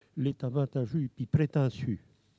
Localisation Notre-Dame-de-Monts
Catégorie Locution